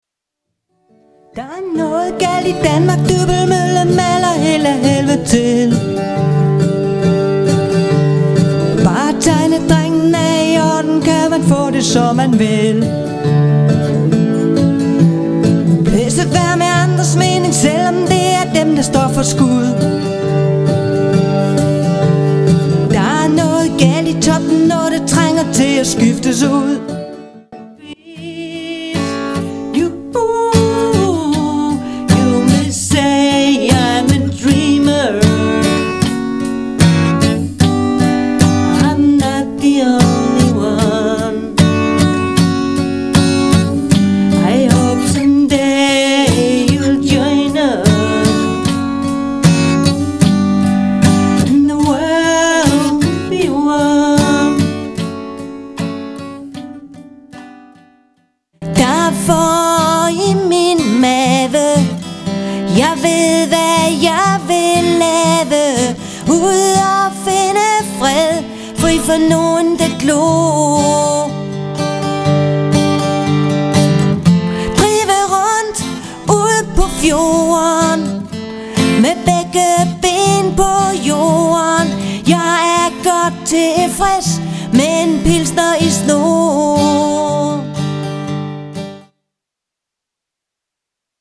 • Lydclip covers (Lydklip covers)